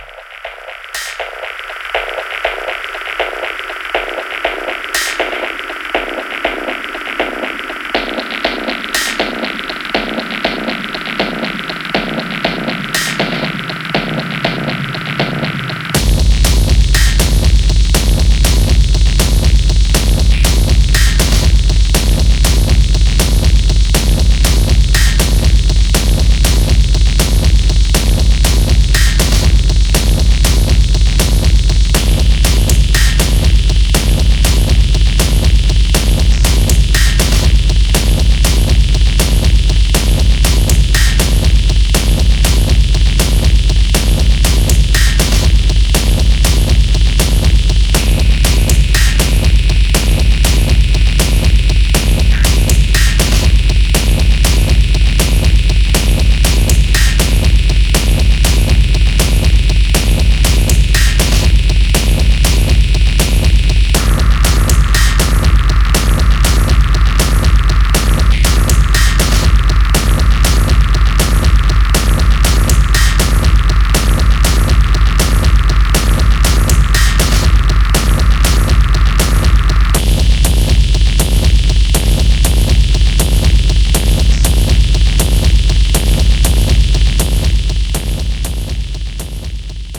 EBM/Industrial